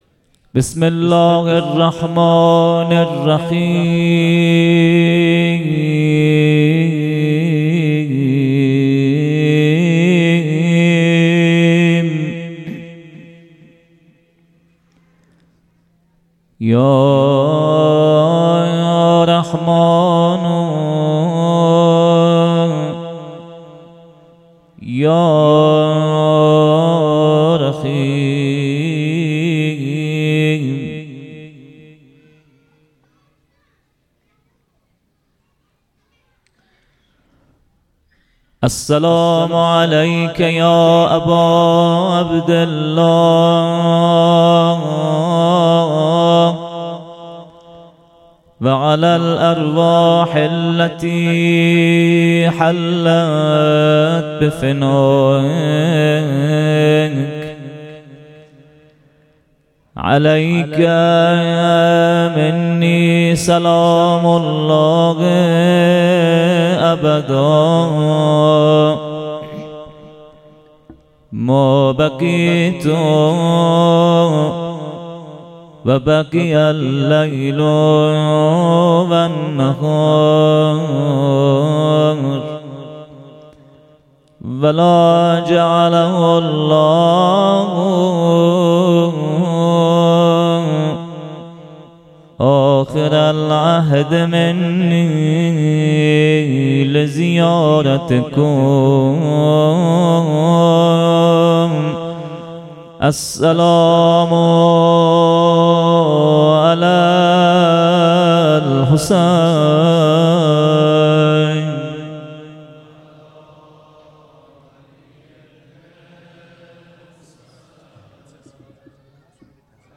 خیمه گاه - هیأت محبان اهل بیت علیهم السلام چایپاره - شب هشتم محرم 98 - مقدمه و روضه